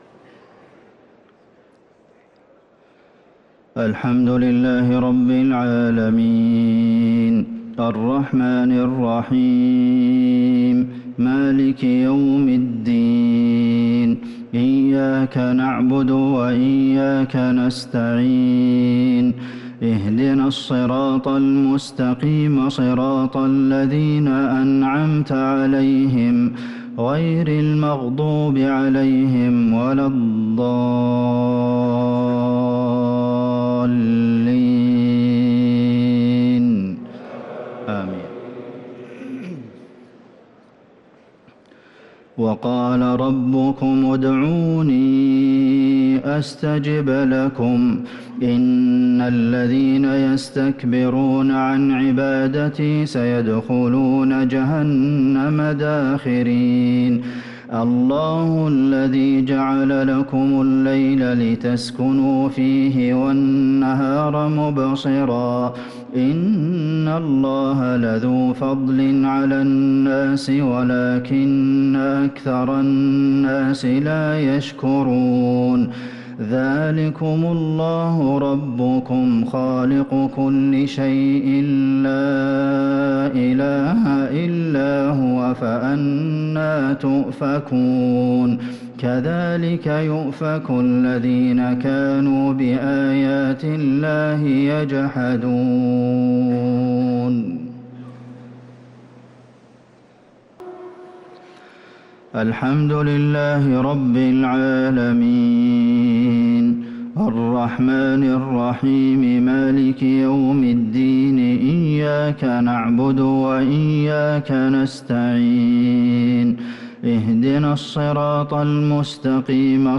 صلاة المغرب للقارئ عبدالمحسن القاسم 27 ذو الحجة 1444 هـ
تِلَاوَات الْحَرَمَيْن .